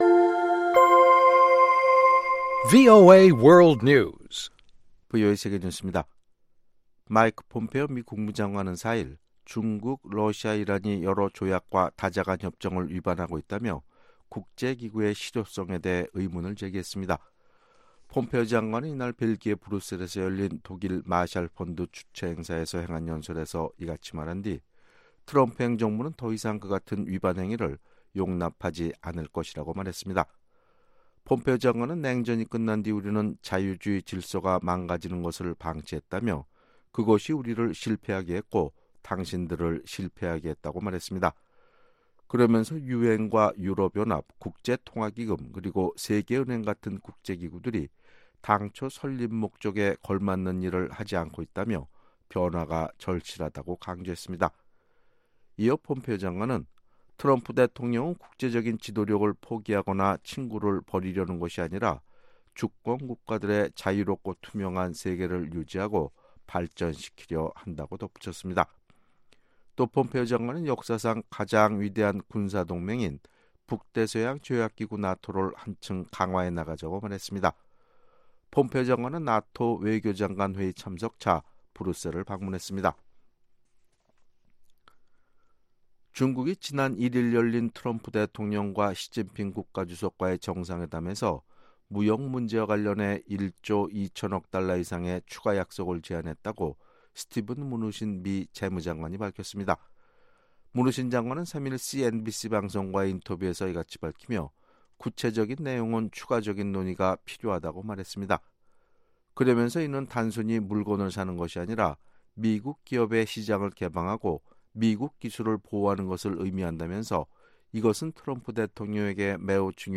VOA 한국어 아침 뉴스 프로그램 '워싱턴 뉴스 광장' 2018년 12월 5일 방송입니다. 중국은 핵 없는 한반도를 위해 미국을 돕겠다고 약속했다고 스티븐 므누신 미 재무장관이 말했습니다. 미국의 5개 연방 정부기관이 돈 세탁방지를 위한 공동성명을 발표했습니다.